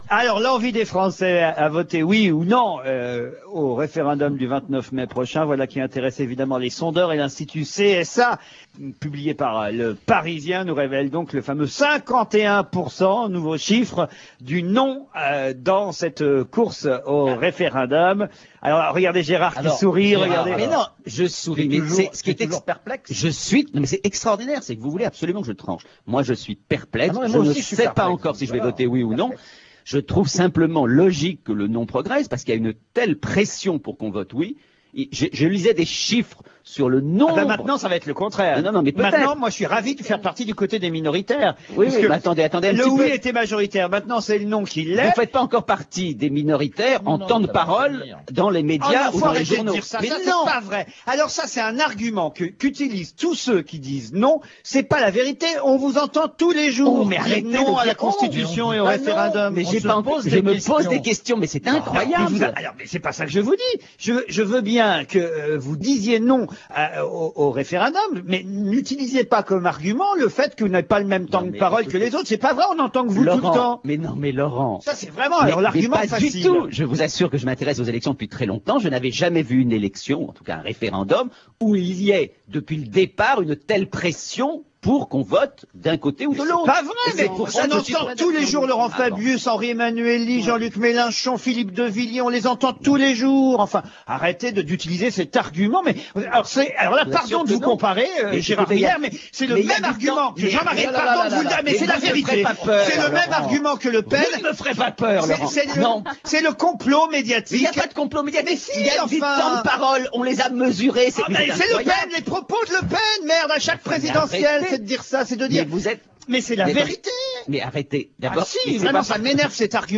Sur Europe 1, Laurent Ruquier soutient, toutes griffes verbales dehors, que l'on n'entend que les partisans du « non ».
Il n’en faut pas plus pour que Laurent Ruquier se lance dans une agression (verbale, certes), dont la virulence n’est pas restituée par une transcription écrite [2] (en gras : souligné par nous).